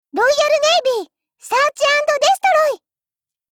Cv-20404_battlewarcry.mp3